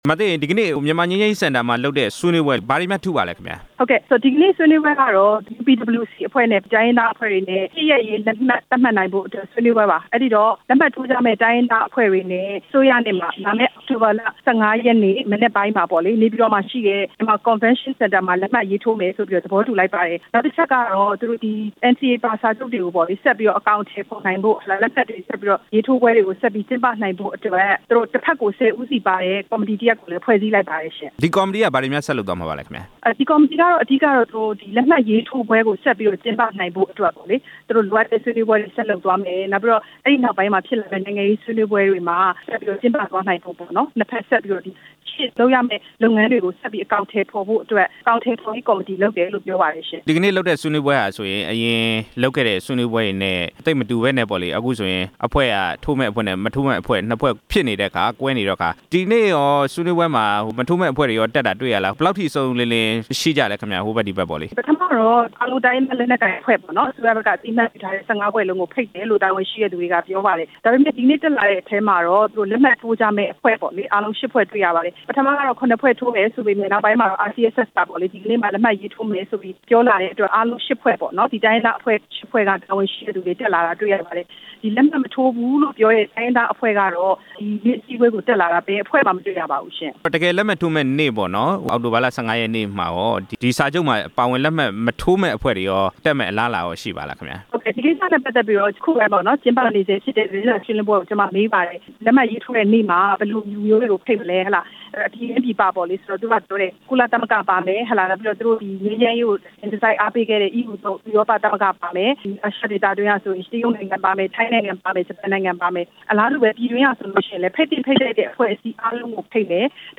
ရန်ကုန်မြို့ မြန်မာငြိမ်းချမ်းရေးစင်တာ MPC ကနေ